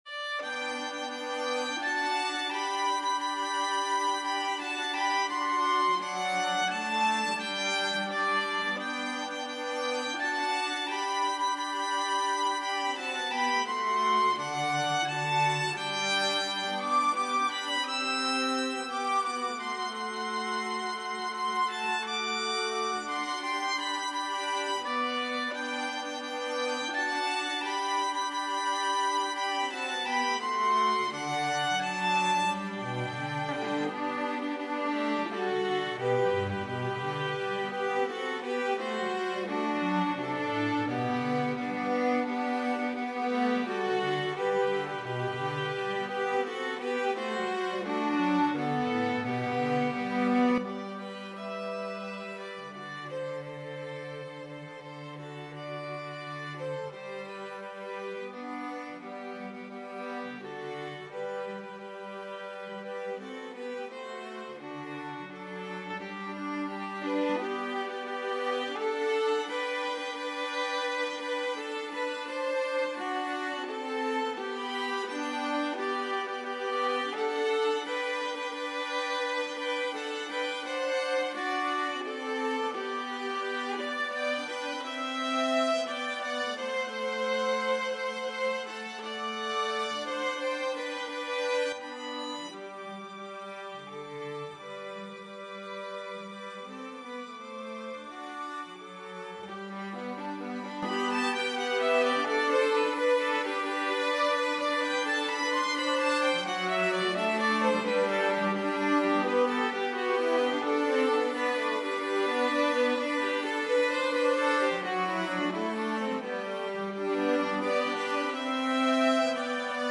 arranged for string trio, is a traditional Christmas carol
Genre: Christmas & Other Holiday , Folk/Traditional